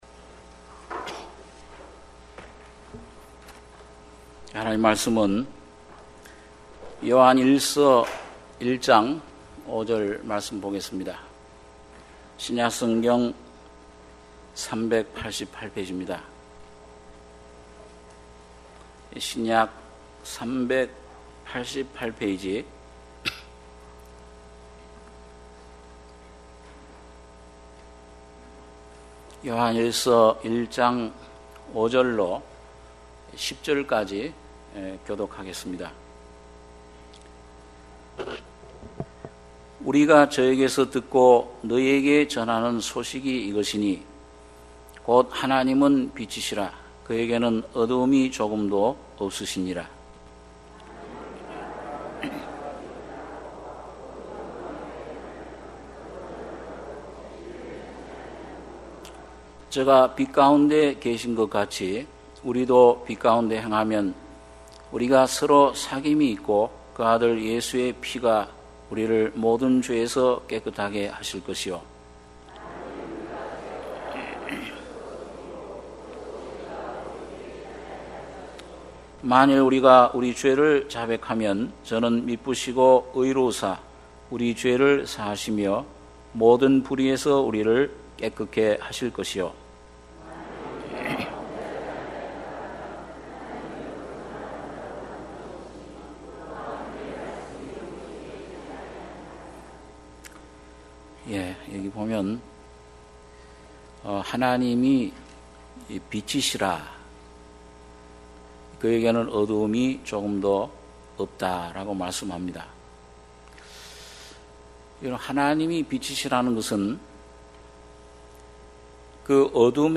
주일예배 - 요한일서 1장 5-10절